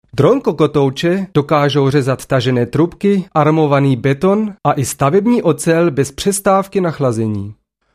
sprecherdemos
tschechisch m_01